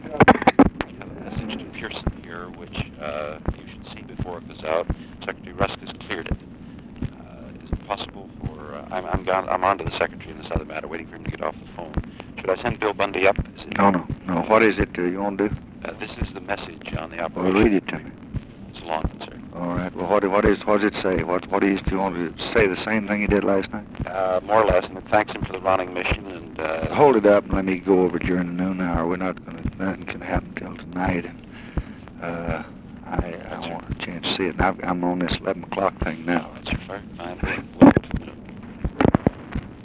Conversation with WALT ROSTOW, June 23, 1966
Secret White House Tapes